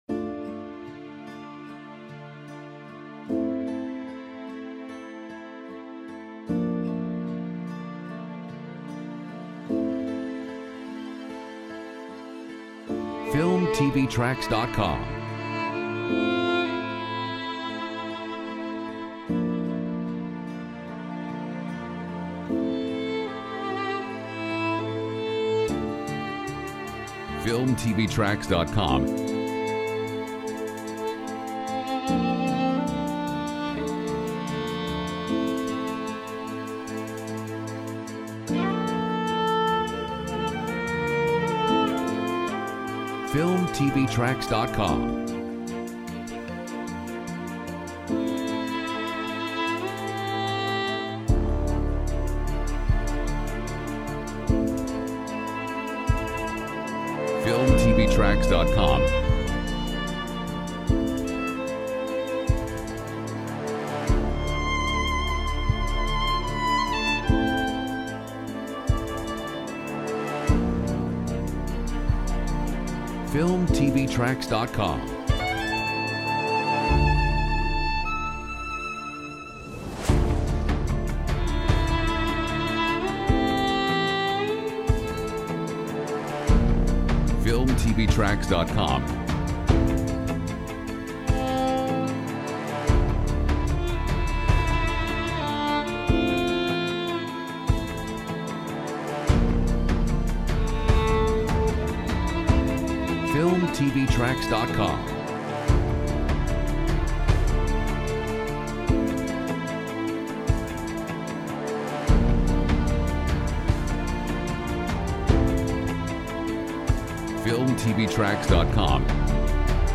Genres: Piano, Orchestral
Mood: Calm, inspiring, uplifting, emotive